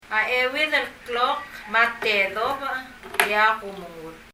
発音
omengur　[ɔmɛŋur]　　食事する　　have a meal